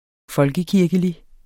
Udtale [ ˈfʌlgəˌkiɐ̯gəli ]